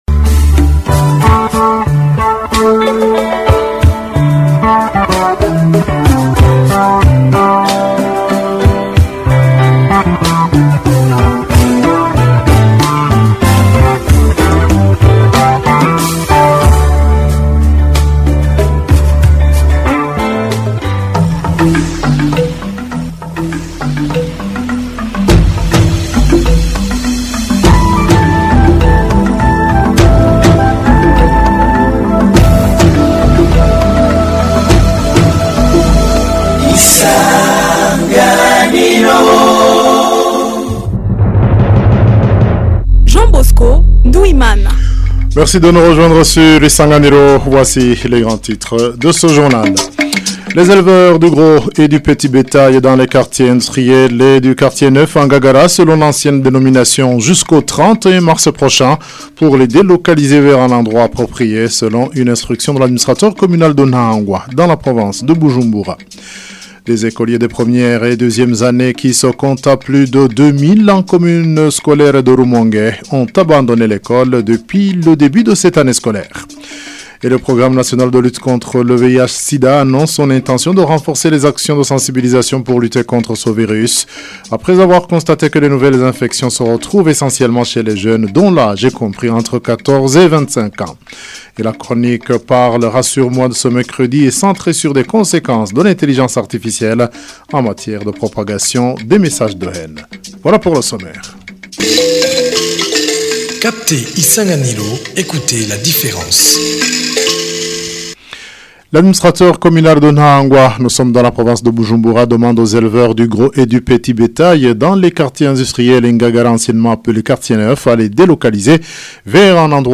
Journal du 18 février 2026